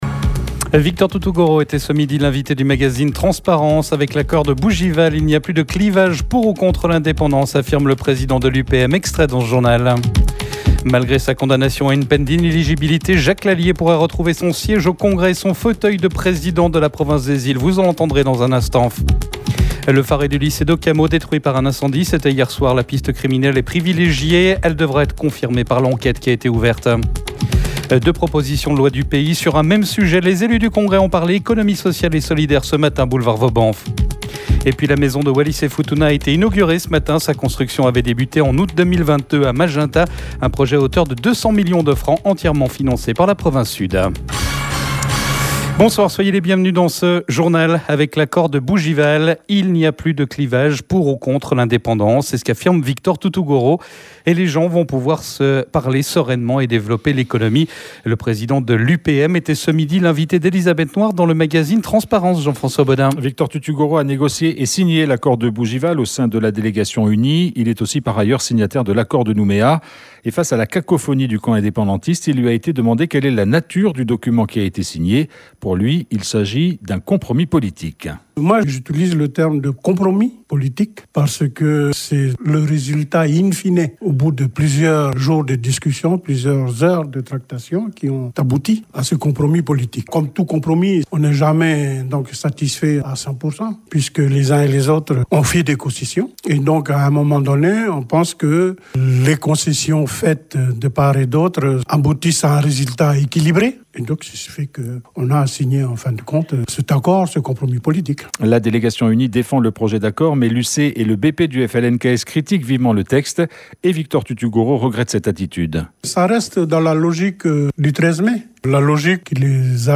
Le journal de la rédaction.